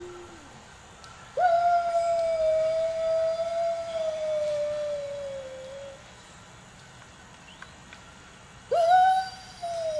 howl_sample.wav